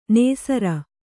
♪ nēsara